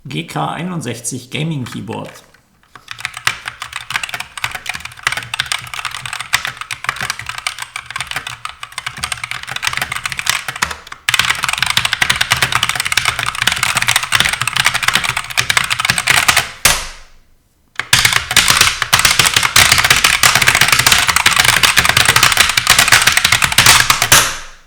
Zusätzliche Schichten schallabsorbierenden Materials streichen die Kandidaten.
Die einfache Konstruktion hat akustisch klare Auswirkungen
GK61 Mechanische Gaming-Tastatur (Gateron Optical Red)